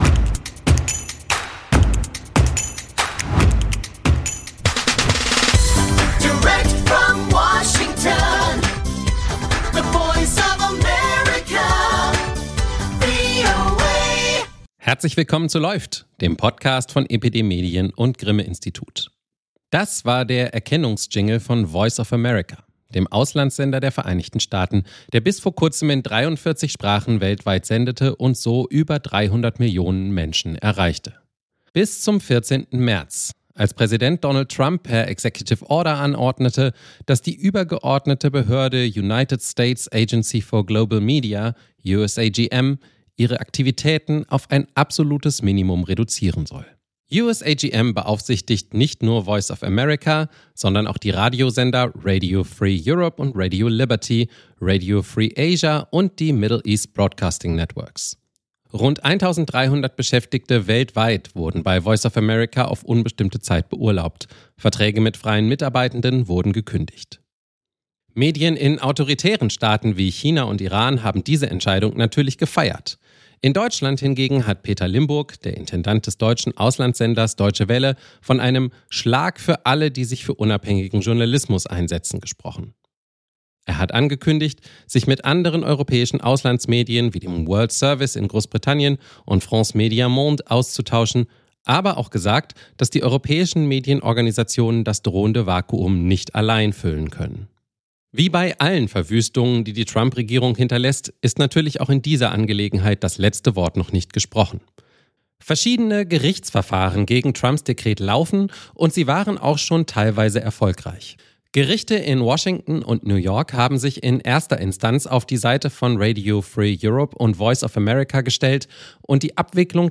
Im Gespräch erzählen sie, wofür sich Podcasts bei Medienthemen besonders gut eignen, welche Rückmeldungen sie bekommen und welche Rolle sie als Personen dabei spielen.